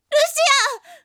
제가 전문가가 아니라서 추출한 음성파일 음질이 좀 미흡하네요.